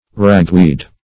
Ragweed \Rag"weed`\ (r[a^]g"w[=e]d`), n. (Bot.)